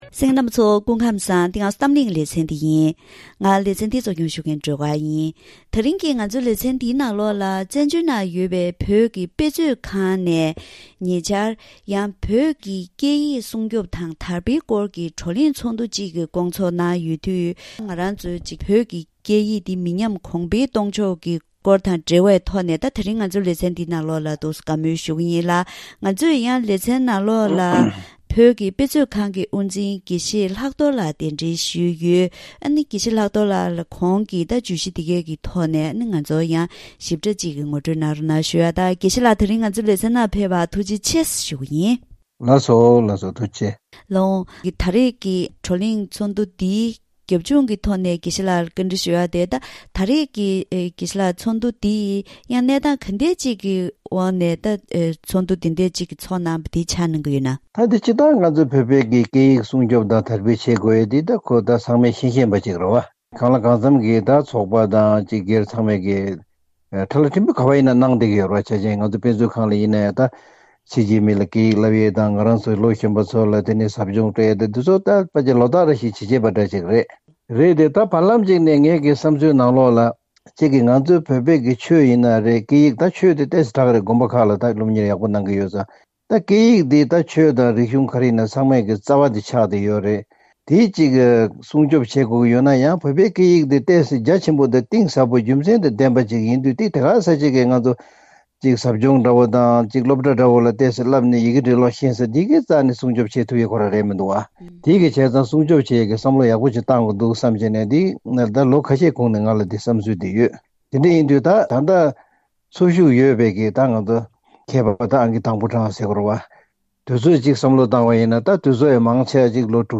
ད་རིང་གི་གཏམ་གླེང་ལེ་ཚན་ནང་བཙན་བྱོལ་ནང་ཡོད་པའི་བོད་ཀྱི་དཔེ་མཛོད་ཁང་ནས་བོད་ཀྱི་སྐད་ཡིག་སྲུང་སྐྱོབ་དང་དར་འཕེལ་སྐོར་གྱི་བགྲོ་གླེང་ཚོགས་འདུ་ཞིག་སྐོང་ཚོགས་གནང་ཡོད་པས། ད་ལྟའི་གནས་སྟངས་ཐོག་ནས་བོད་ཀྱི་སྐད་ཡིག་མི་ཉམས་གོང་འཕེལ་གཏོང་ཕྱོགས་སོགས་ཀྱི་སྐོར་ལ་བཀའ་མོལ་ཞུས་ཞིག་གསན་རོགས་གནང་།